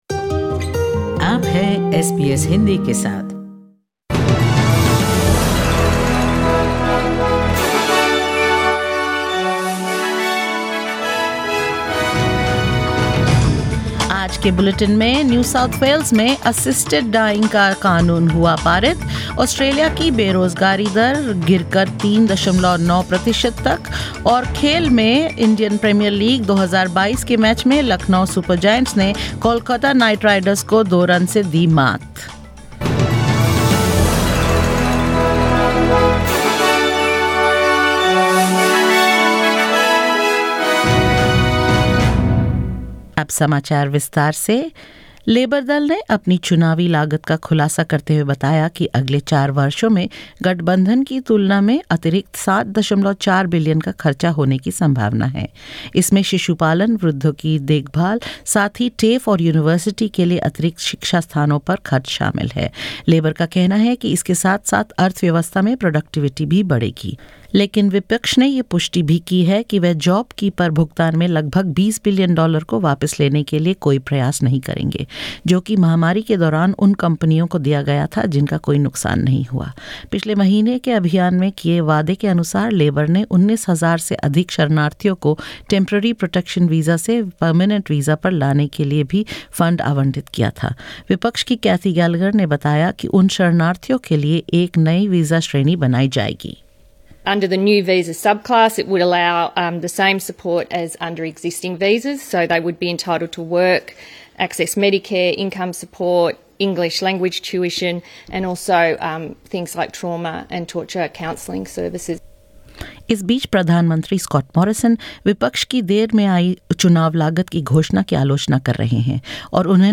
In this latest SBS Hindi bulletin: Labor unveils its election costings adding $7.4b to the budget deficit; Voluntary assisted dying becomes legal in New South Wales; Australia's unemployment rate drops to a low of 3.9 per cent; In IPL 2022, Lucknow Super Giants beat Kolkata Knight Riders by two runs and more.